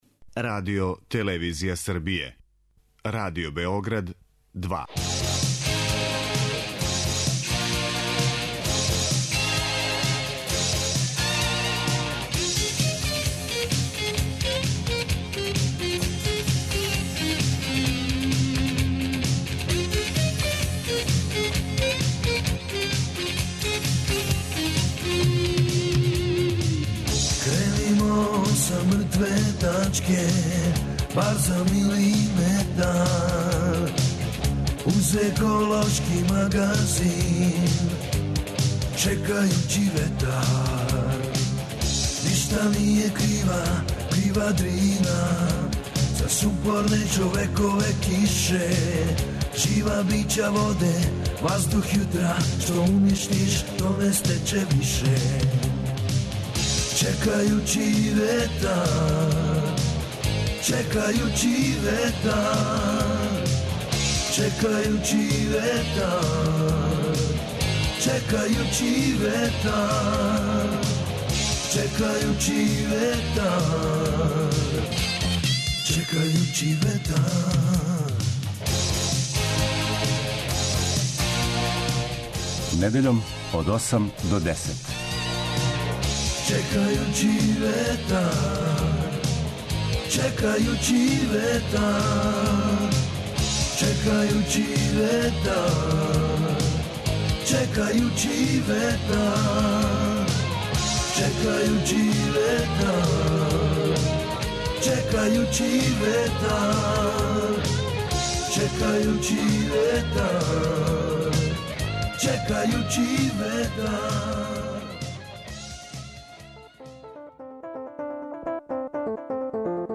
Еколошки магазин